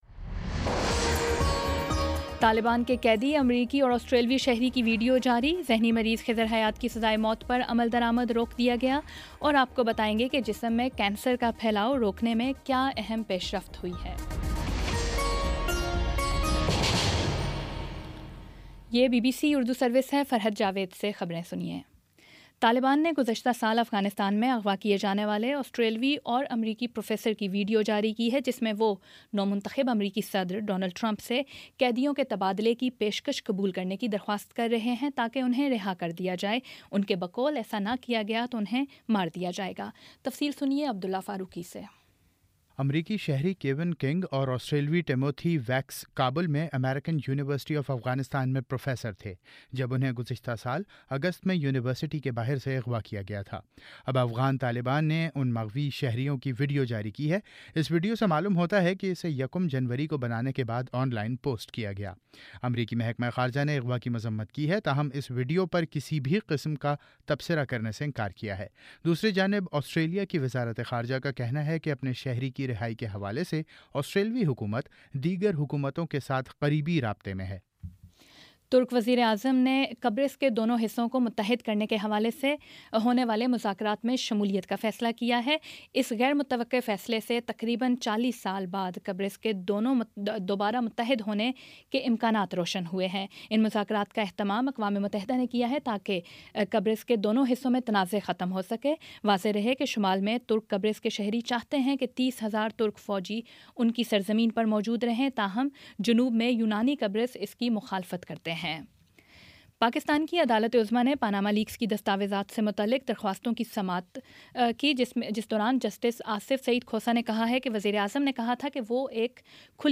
جنوری 12 : شام پانچ بجے کا نیوز بُلیٹن